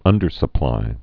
(ŭndər-sə-plī)